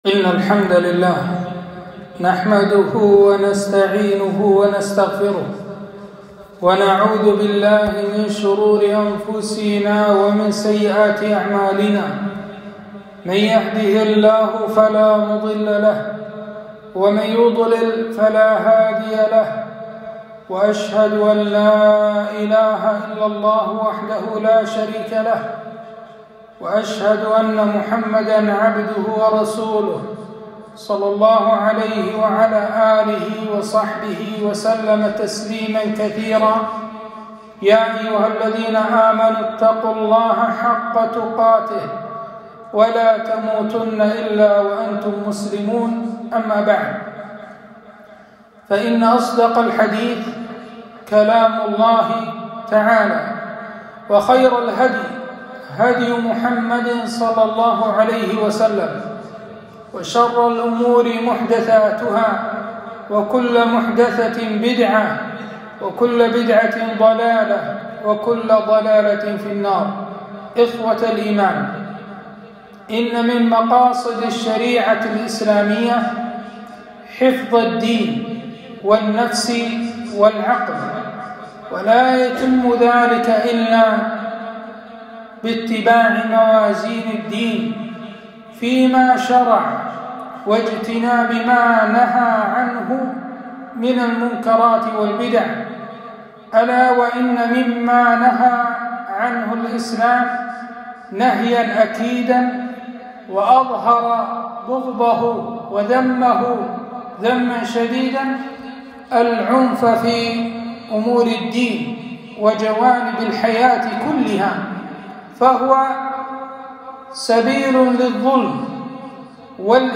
خطبة - التحذير من جريمة القتل